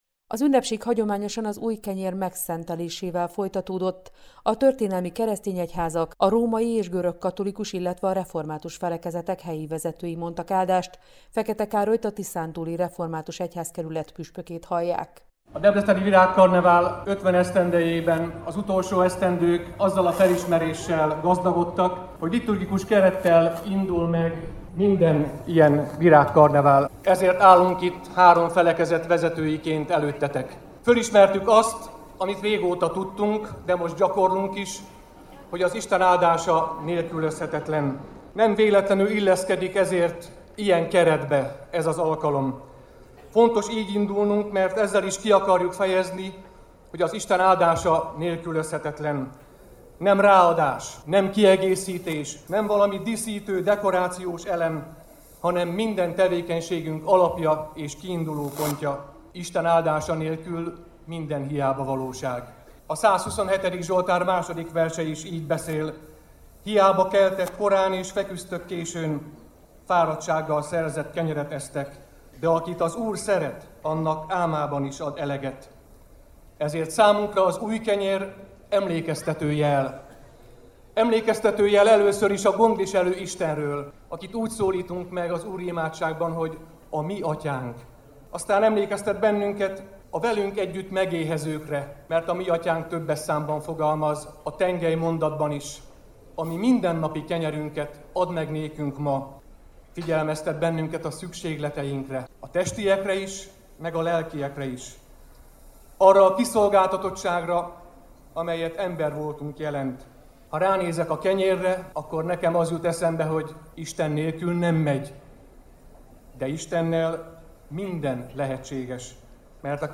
puspok-kenyerszenteles-koszonto.mp3